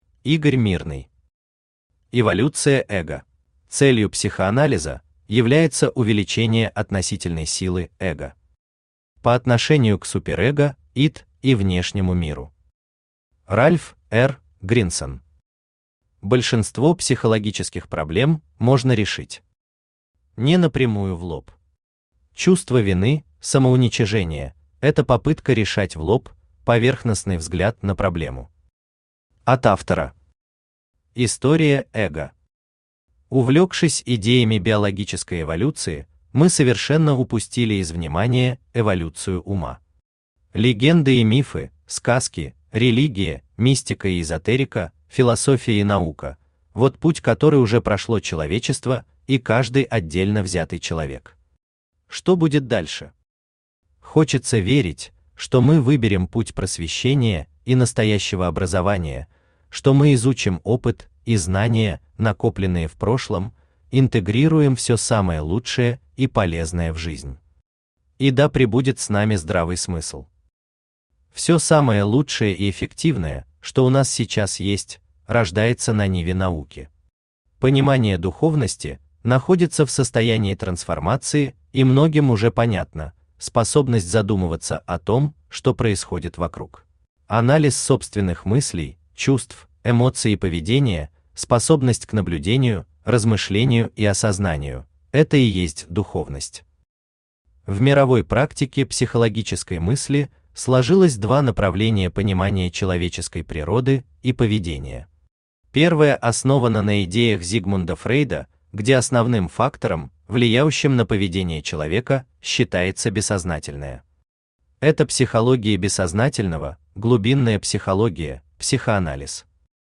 Аудиокнига Эволюция Эго | Библиотека аудиокниг
Aудиокнига Эволюция Эго Автор Игорь Владимирович Мирный Читает аудиокнигу Авточтец ЛитРес.